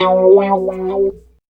28 GUIT 1 -L.wav